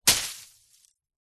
Удар палкой по траве или растению